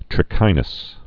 (trĭ-kīnəs, trĭkə-nəs)